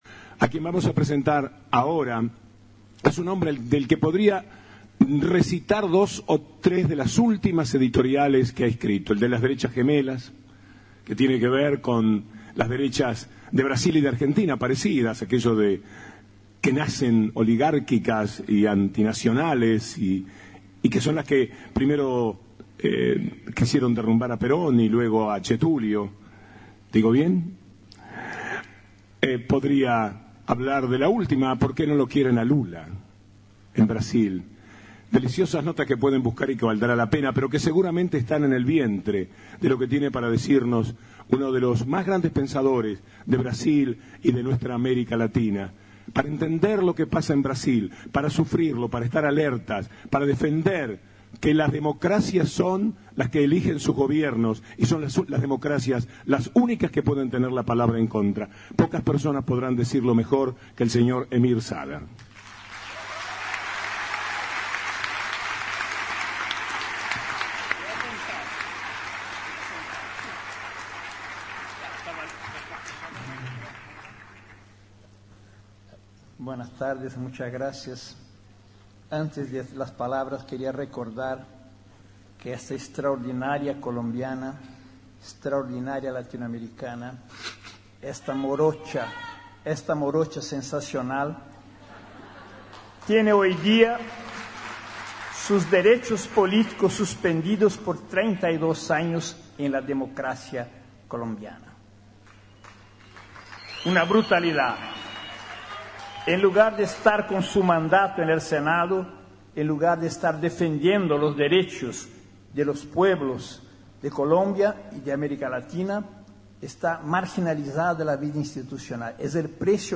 En el Teatro Cervantes de la Ciudad de Buenos Aires